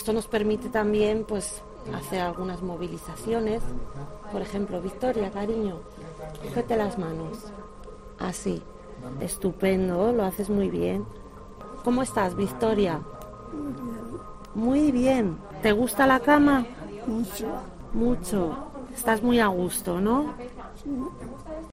fisioterapeuta, explica como funciona la cama de agua vibroacústica